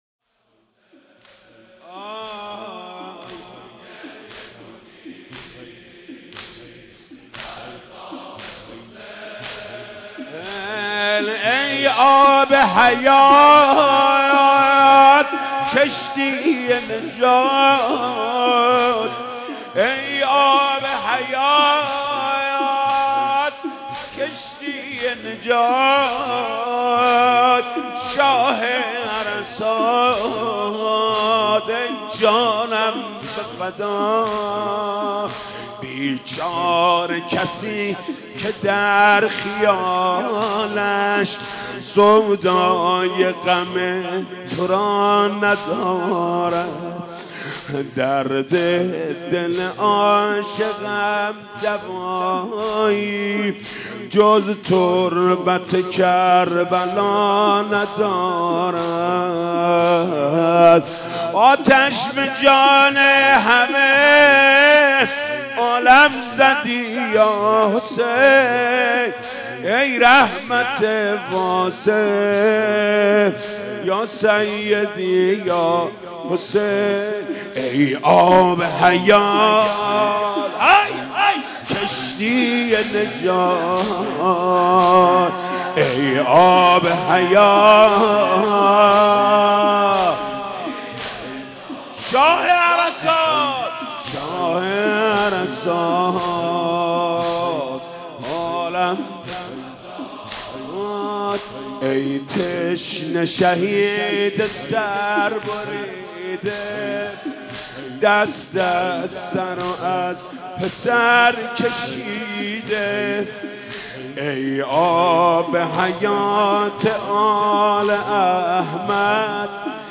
«شهادت امام صادق 1396» زمینه: ای آب حیات کشی نجات